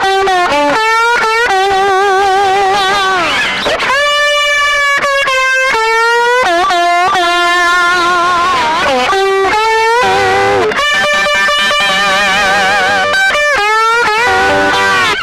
Index of /90_sSampleCDs/Best Service ProSamples vol.17 - Guitar Licks [AKAI] 1CD/Partition D/VOLUME 007